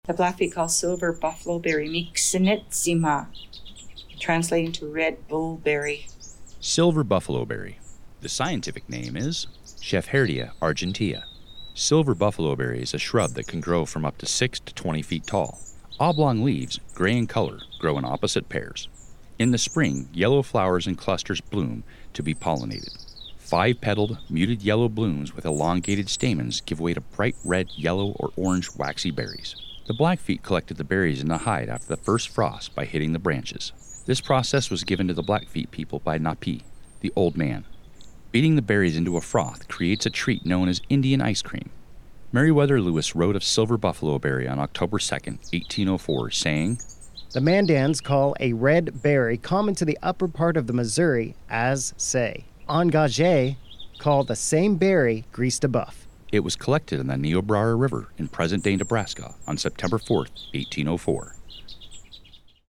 Narration: